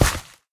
gravel3.mp3